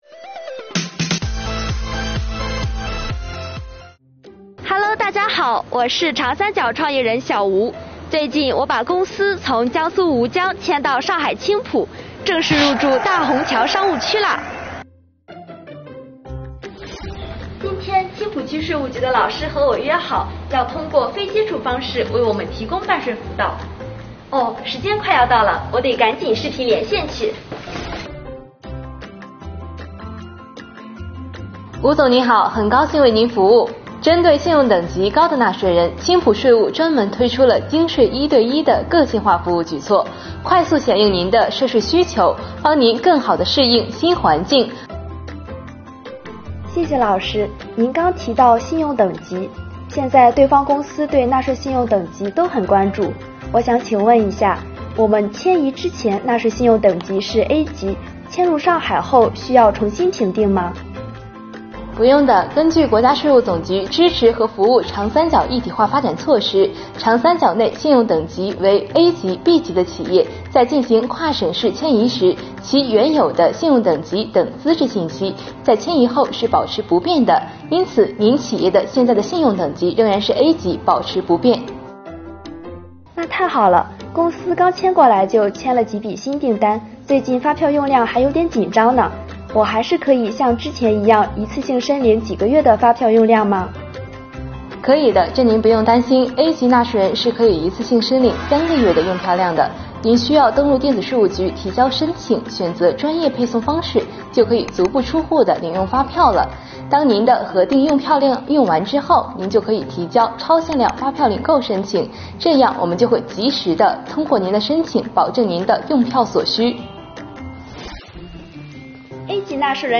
作品通过视频连线的对话方式，为纳税人解答了跨省（市）迁移后纳税信用等级是否需要重新评定的疑惑，展示了优质纳税信用对企业的重要性以及“非接触式”办税给纳税人带来的便利。